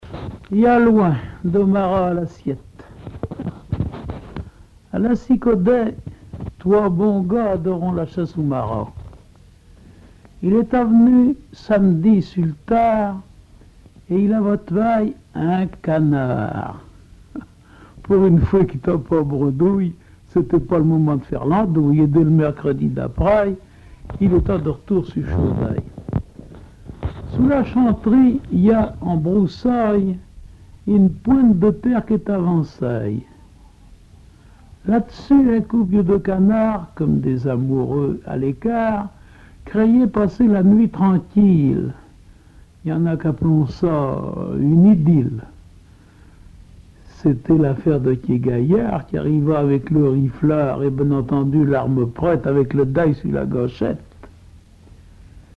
Patois local
Genre récit
Récits et chansons en patois